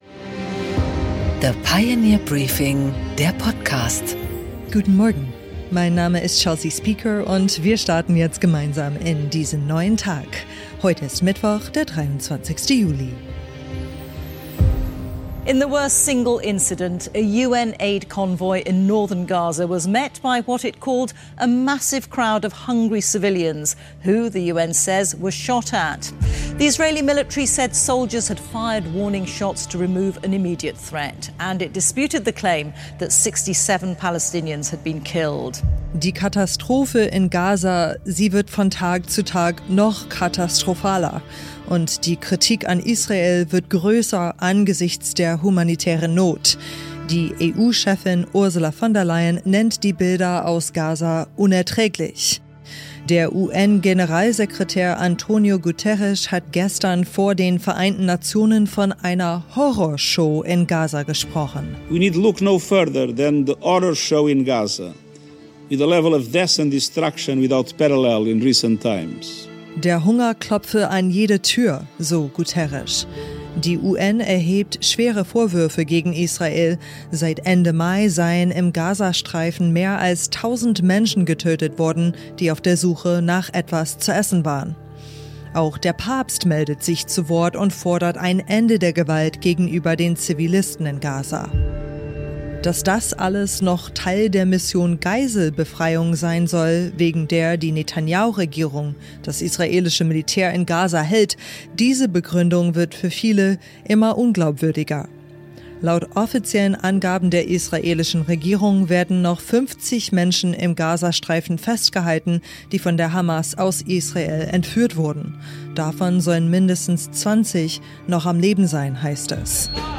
Im Gespräch: Ben Hodges